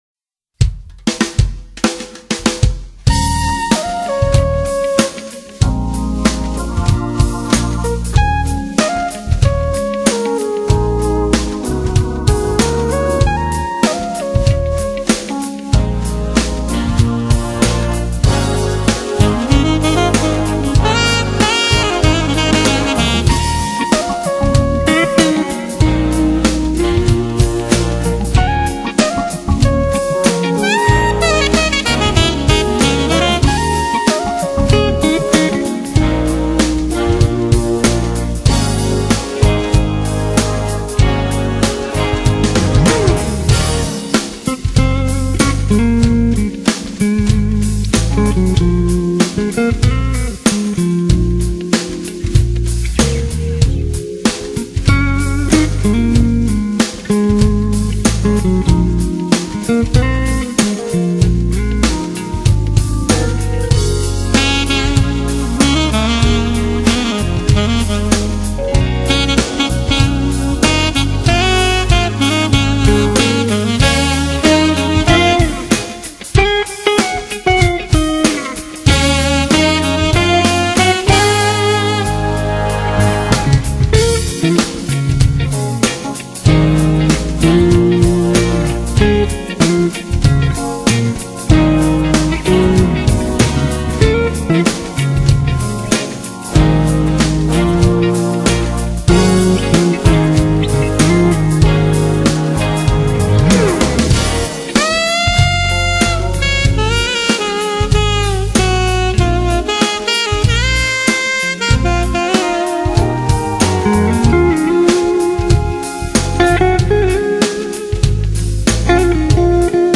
音乐类型：Jazz
keyboards, guitar, drum & percussion programming
vocals
soprano  saxophone
alto  saxophone
saxophone,  flute
trombone,  baritone  horn
piano
acoustic  bass
bass